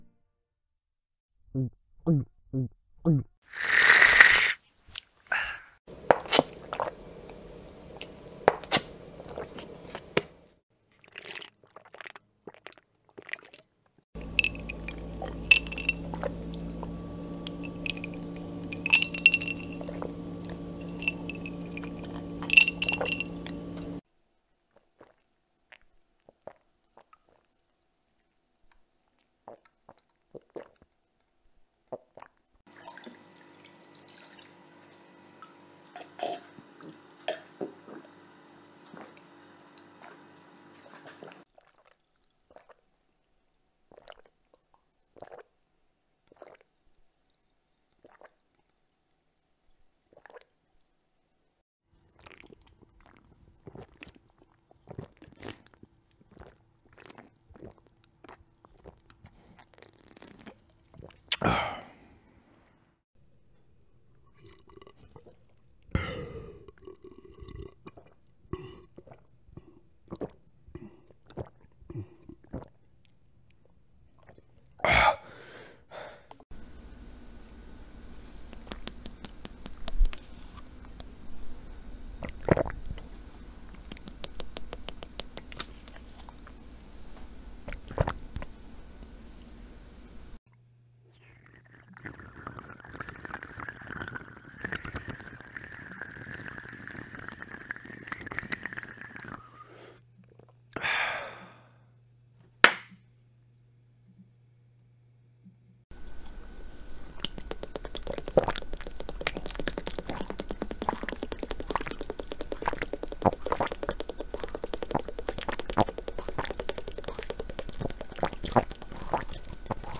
دانلود آهنگ آب خوردن انواع مختلف از افکت صوتی انسان و موجودات زنده
دانلود صدای آب خوردن انواع مختلف از ساعد نیوز با لینک مستقیم و کیفیت بالا
جلوه های صوتی